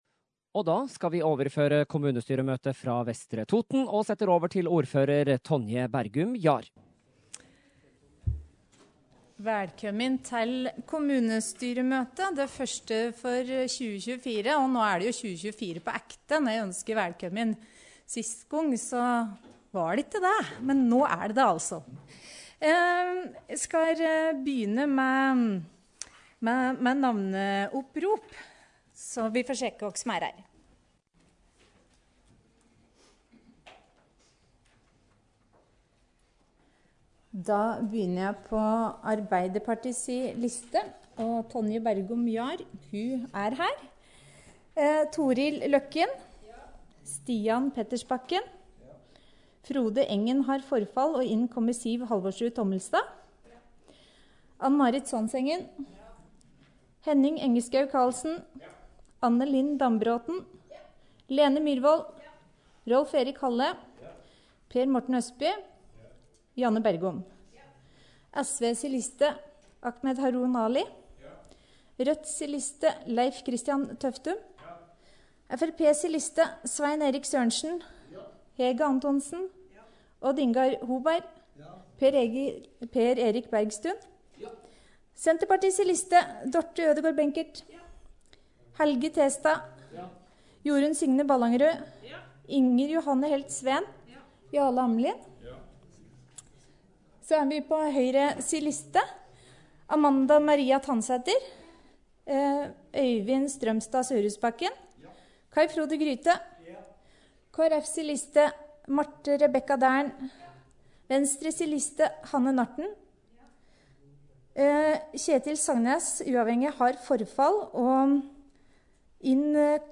Kommunestyremøte fra Vestre Toten 1. februar – Lydfiler lagt ut | Radio Toten